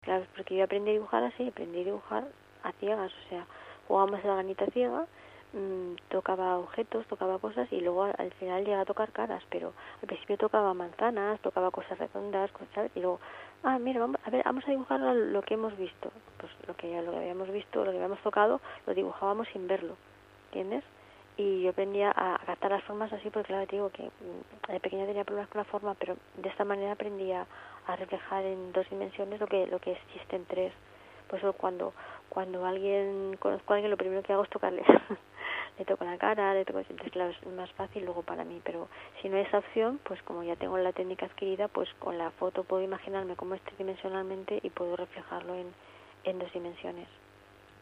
suena cercana, increíblemente dulce, aniñada, con colores de tonos suaves, casi infantiles.